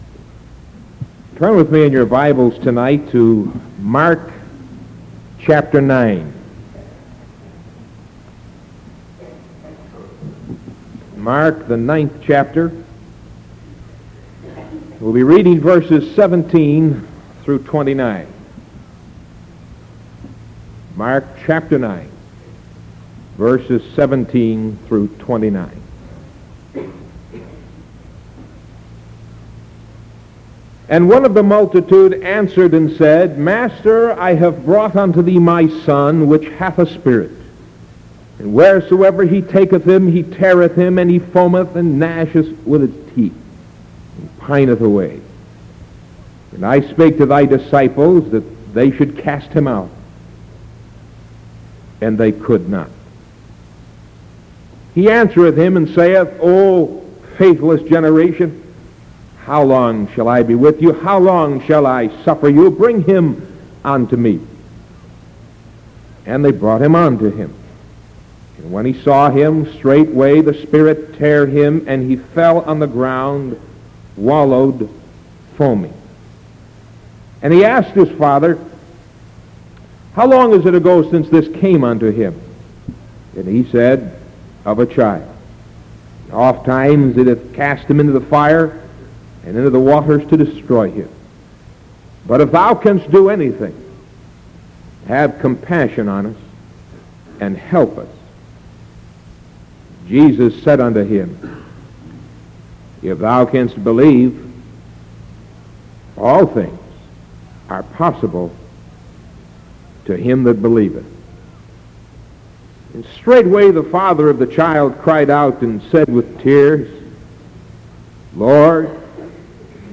Sermon from October 21st 1973 PM